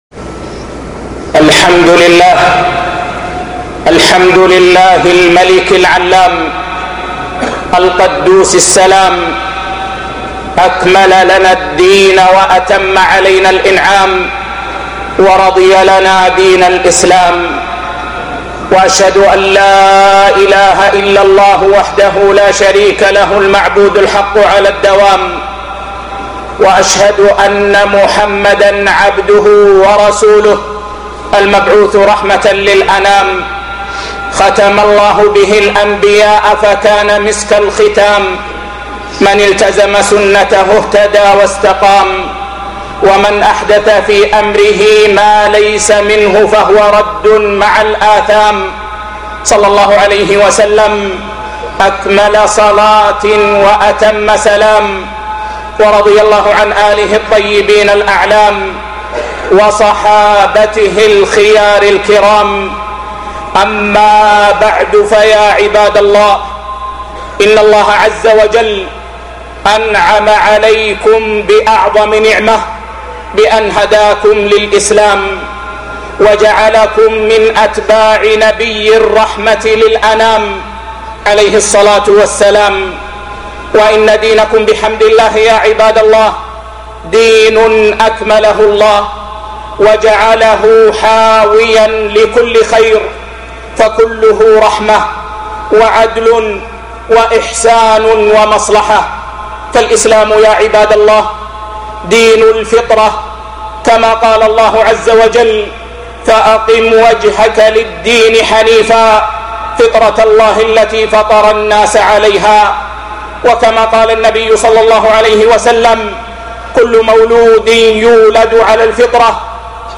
محاسن الدين الاسلامي - خطبة